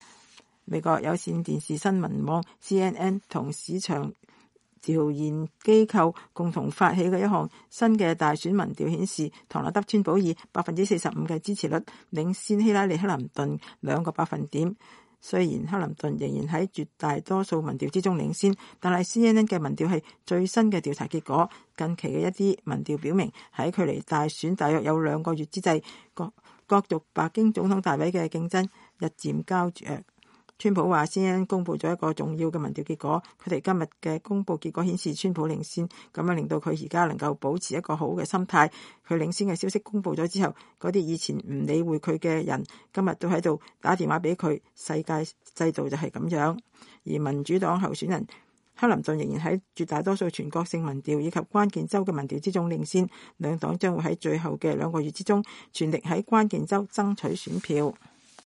共和黨人唐納德川普在維吉尼亞州探討外交政策時喜不自勝地談到了CNN這一最新民調顯示的好消息。
在佛羅里達州一場競選集會中，克林頓對川普展開進攻。